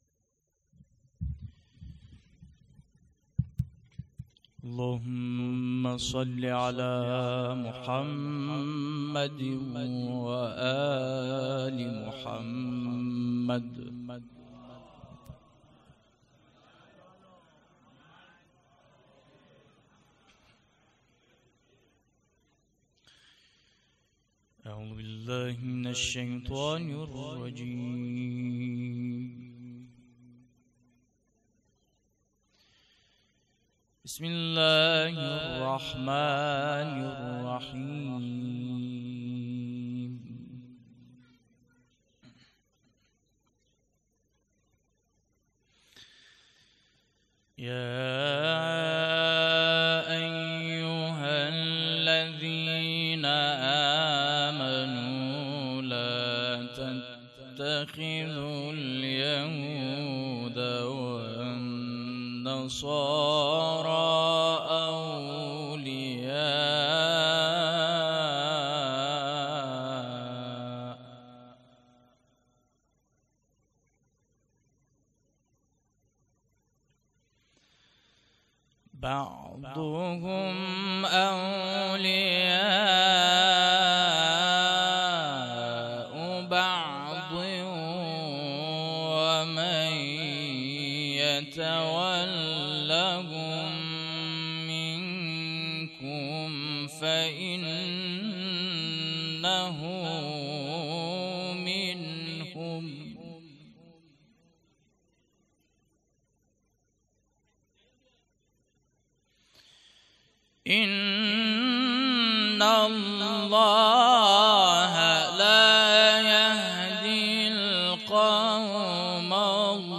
قرائت قرآن
شب سوم جشن عید غدیر ۱۴۰۴